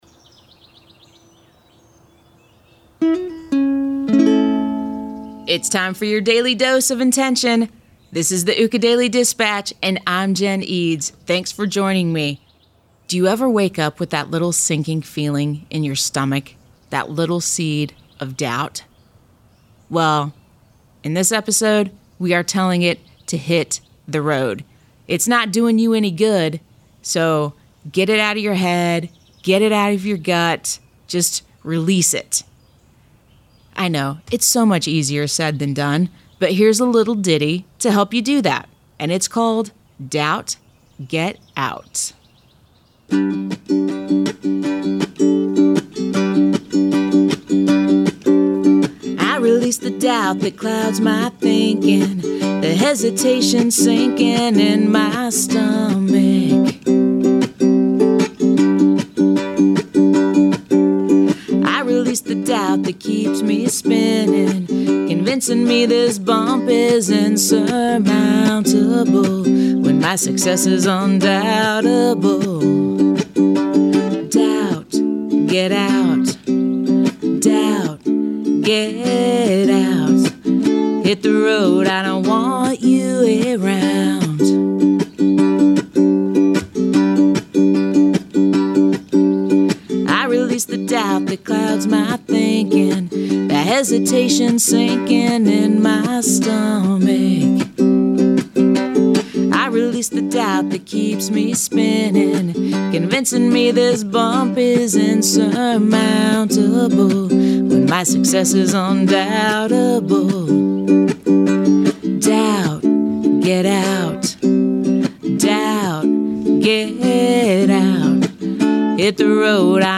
Here's a little ditty to help you get started.